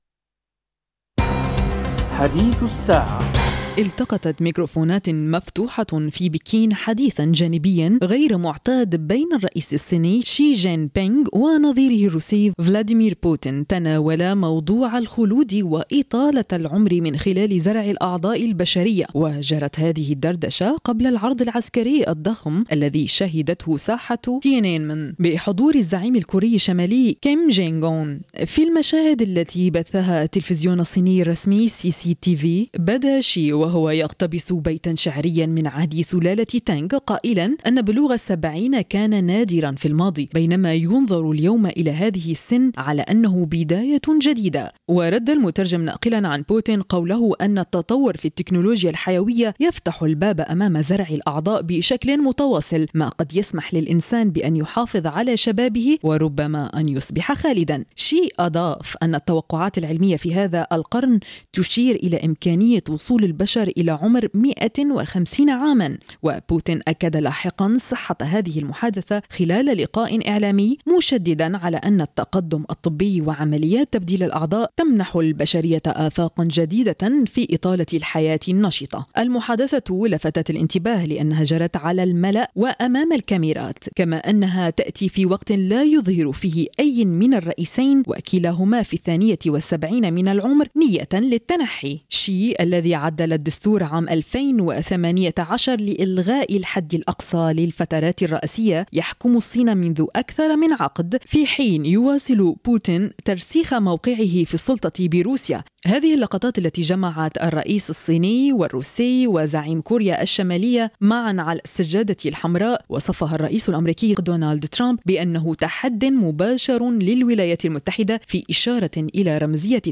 إعداد وتقديم